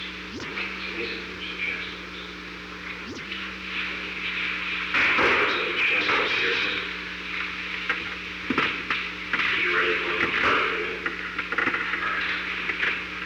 Secret White House Tapes
Conversation No. 520-5
Location: Oval Office